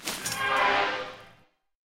DeathrestCasket_Exit_01d.wav